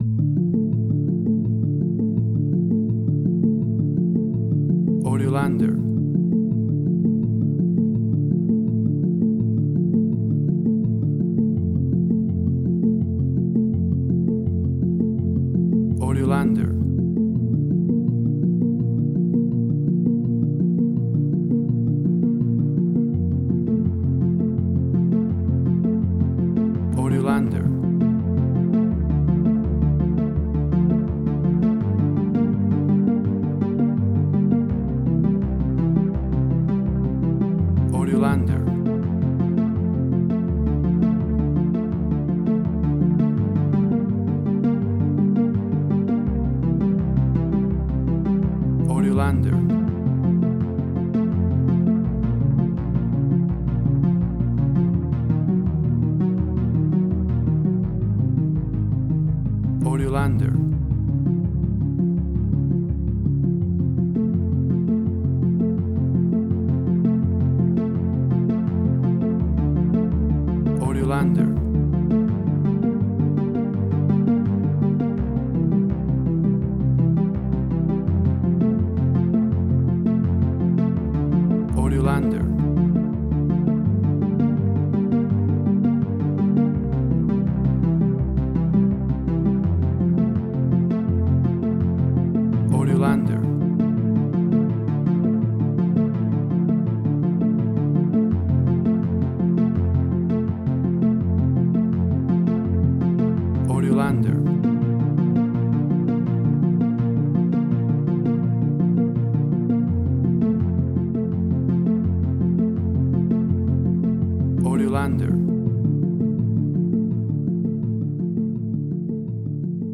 WAV Sample Rate: 16-Bit stereo, 44.1 kHz
Tempo (BPM): 166